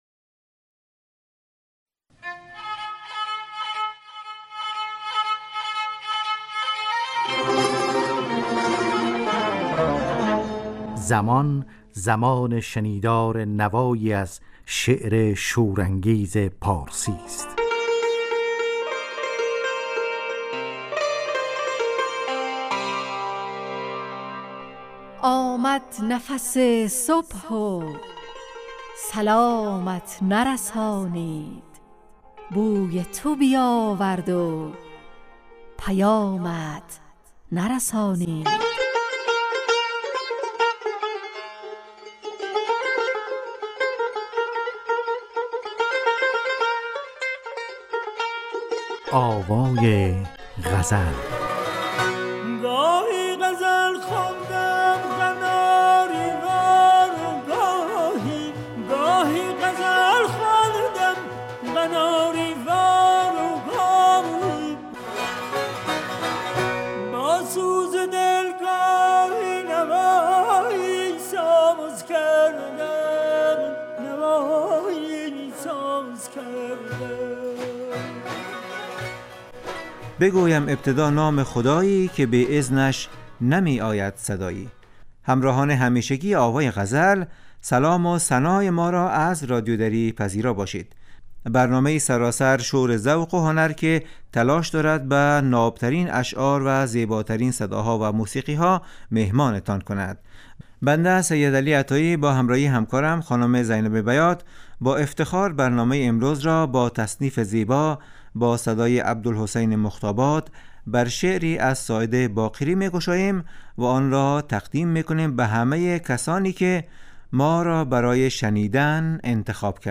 آوای غزل نواهنگ رادیویی در جهت پاسداشت زبان و ادبیات فارسی . خوانش یک غزل فاخر از شاعران پارسی گوی و پخش تصنیف زیبا از خوانندگان نامی پارسی زبان .